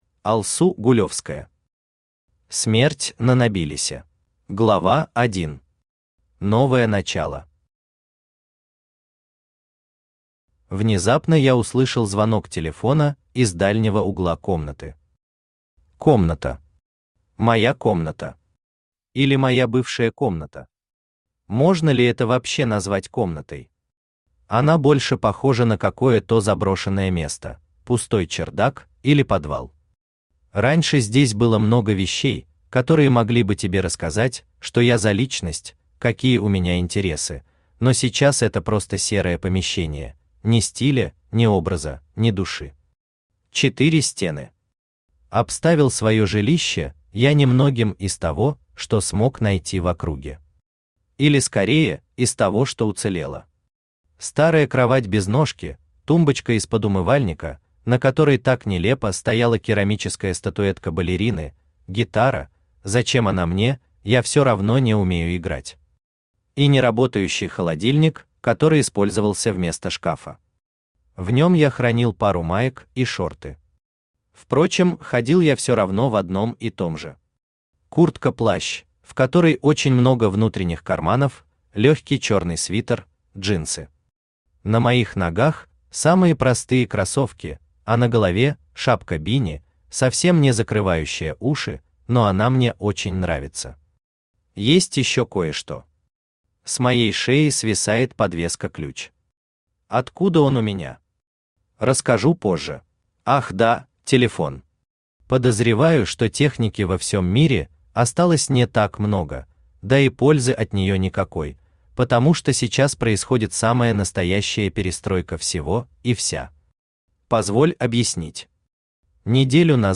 Аудиокнига Смерть на Нобилисе | Библиотека аудиокниг
Aудиокнига Смерть на Нобилисе Автор Алсу Гулевская Читает аудиокнигу Авточтец ЛитРес.